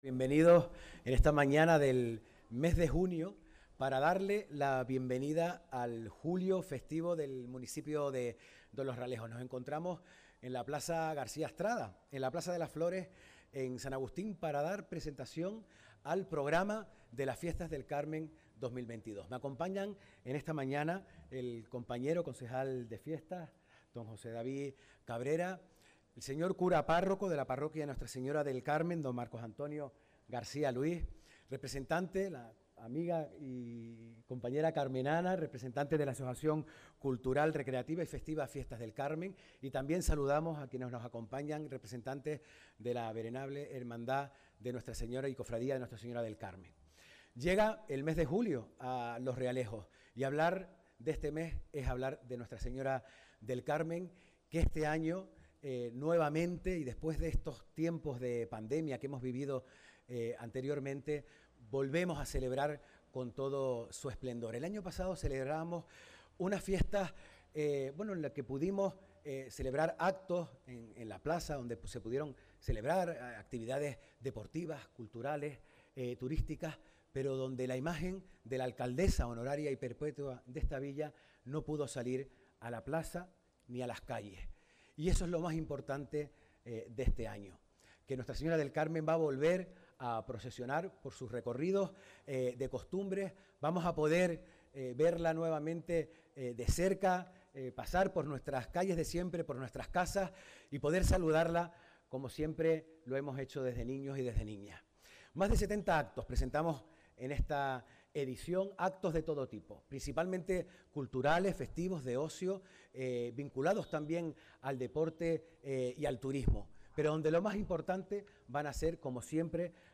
Los Realejos. Presentación de las Fiestas del Carmen. 29 de junio - Gente Radio